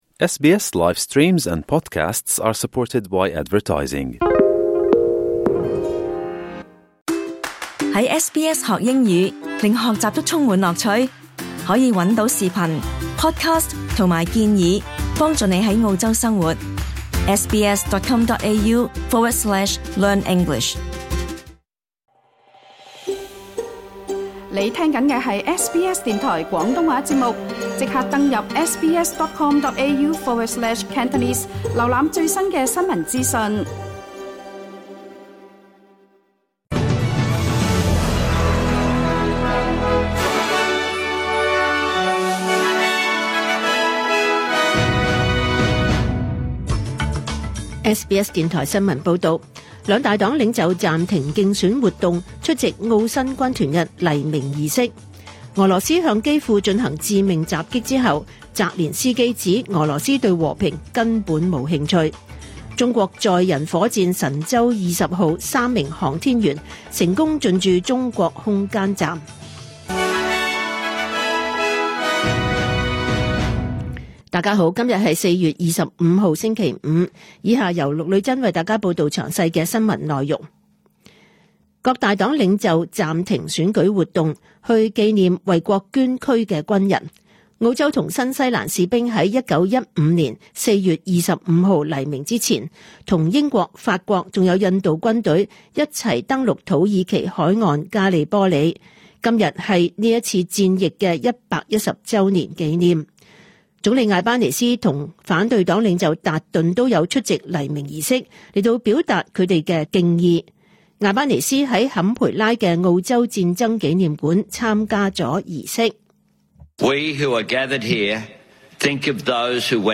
2025 年 4 月 25 日 SBS 廣東話節目詳盡早晨新聞報道。